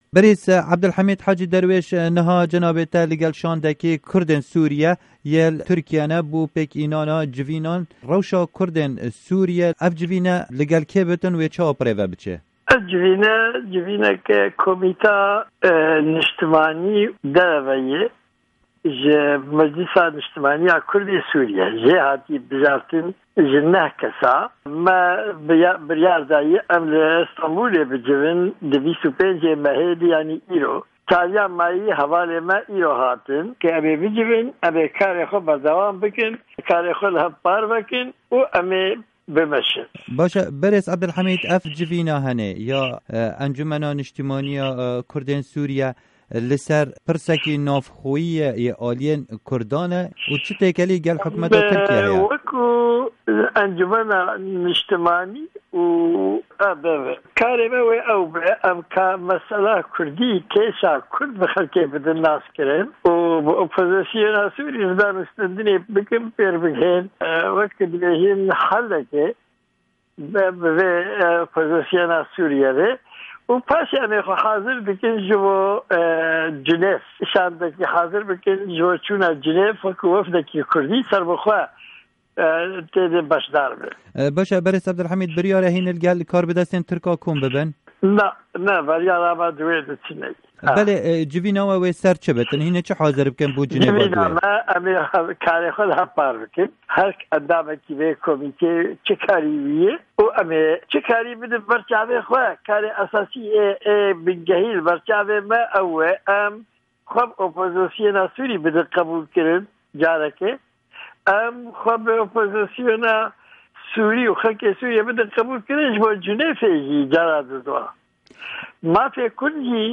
هه‌ڤپه‌یڤین دگه‌ل عه‌بدۆلحه‌مید ده‌روێش سکرتێرێ پارتیا دیموکراتا پێشڤه‌روا کوردین سوریا